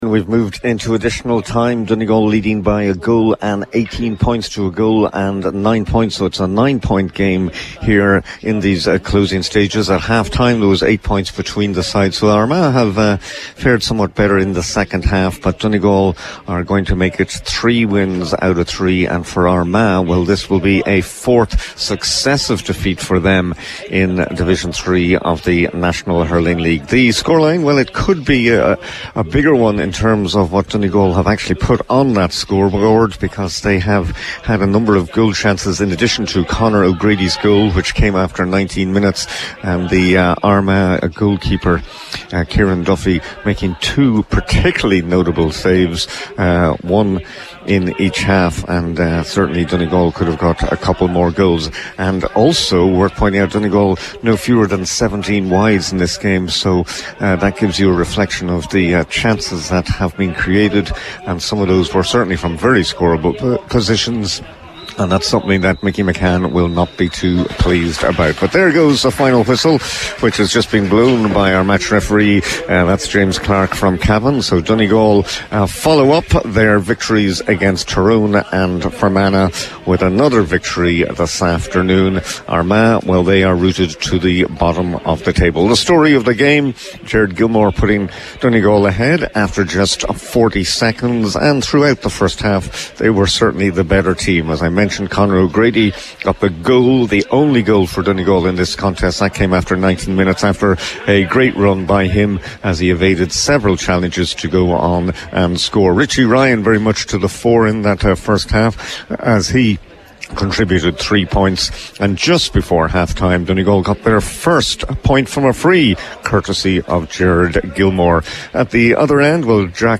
was live at full time for Highland Radio Sport…